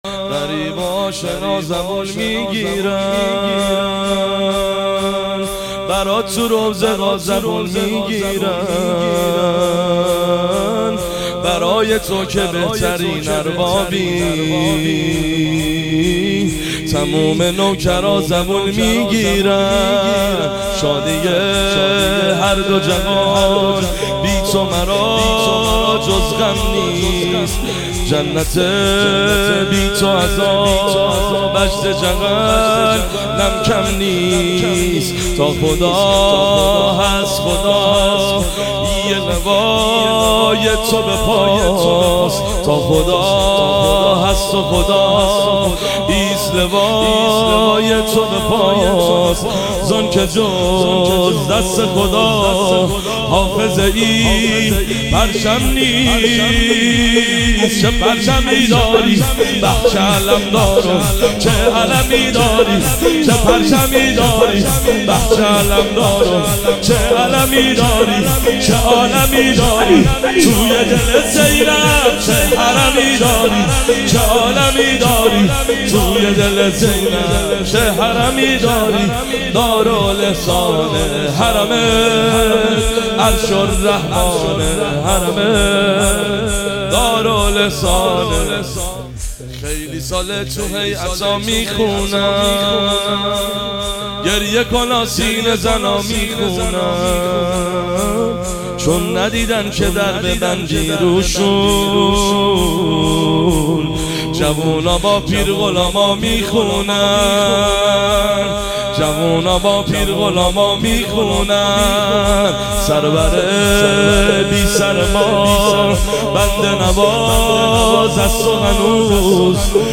محرم 1400 | هیئت لواالزینب (س) قم